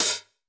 CLF Open Hat.wav